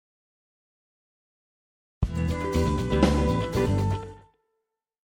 Все, осталось решить последнюю острую проблему, и мой переезд на рипер можно считать совершенным Проблема следующая: при рендере начало получается смазанным... Только бочка проскакивет, а при реалтайм-проигрывании атака у всех инструментов очень даже жесткая.